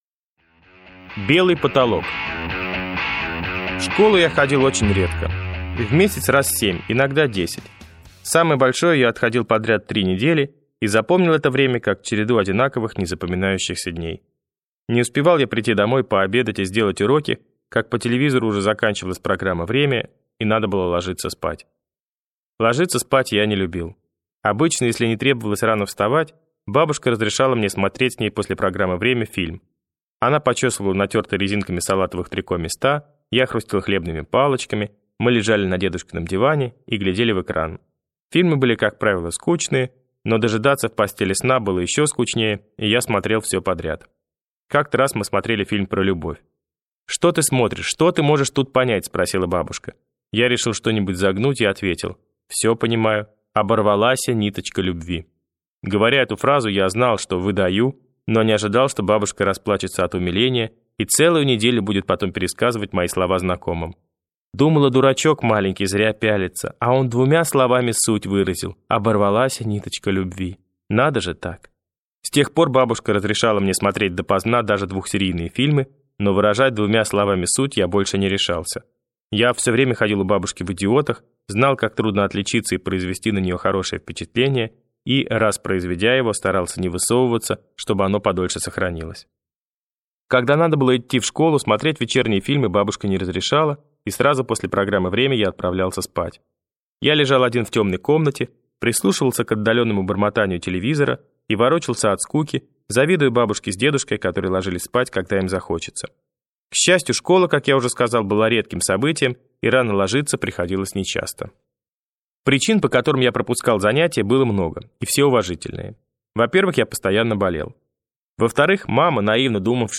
Аудиокнига Похороните меня за плинтусом | Библиотека аудиокниг
Aудиокнига Похороните меня за плинтусом Автор Павел Санаев Читает аудиокнигу Павел Санаев.